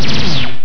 Laser7
LASER7.WAV